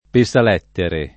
vai all'elenco alfabetico delle voci ingrandisci il carattere 100% rimpicciolisci il carattere stampa invia tramite posta elettronica codividi su Facebook pesalettere [ pe S al $ ttere o pe S al % ttere ] s. m.; inv.